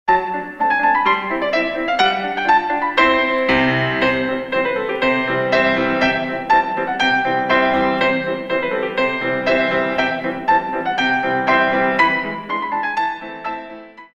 32 Counts